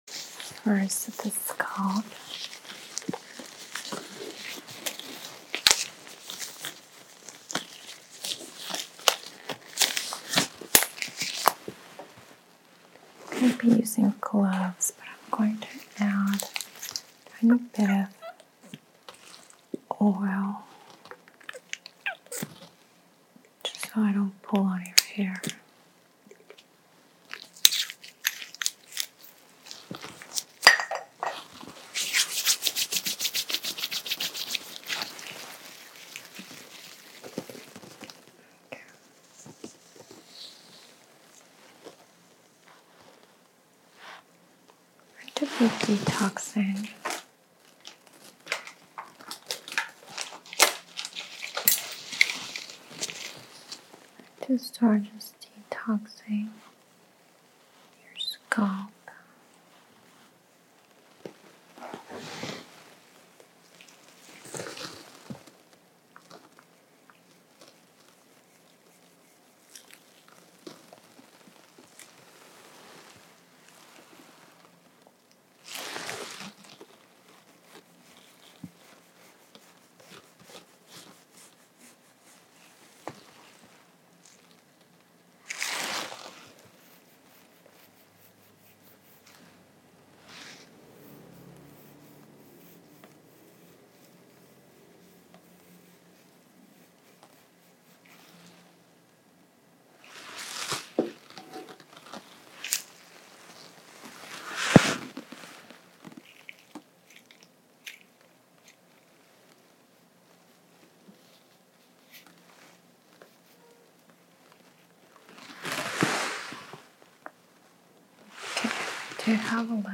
ASMR Hair Cracking (Pulling) & sound effects free download
ASMR Hair Cracking (Pulling) & Ear Seeds Therapy | Tingles, Scalp Care Triggers & Deep Relaxation